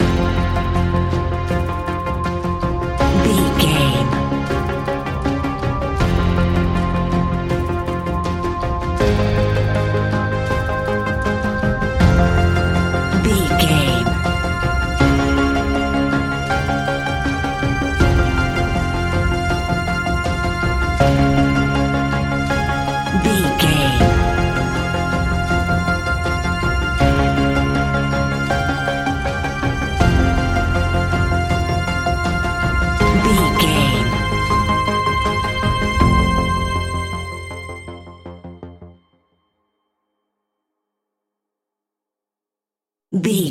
Aeolian/Minor
ominous
dark
haunting
eerie
industrial
synthesiser
drums
horror music